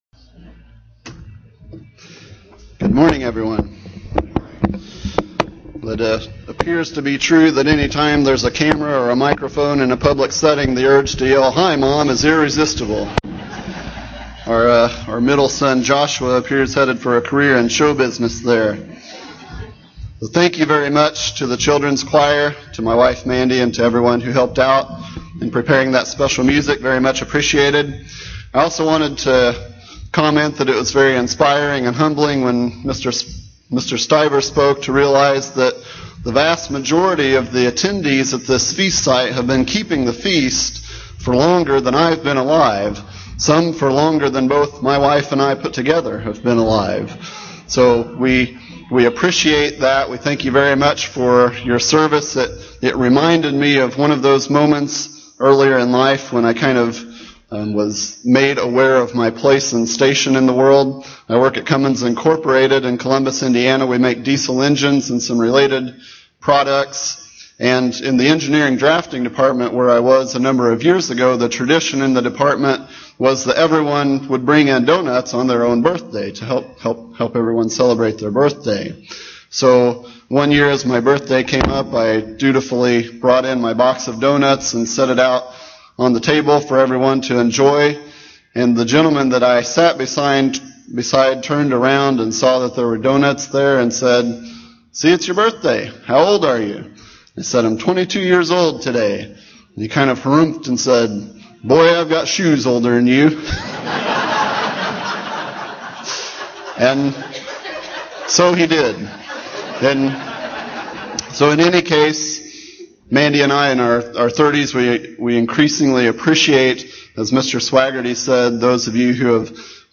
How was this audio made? This sermon was given at the Cincinnati, Ohio 2013 Feast site.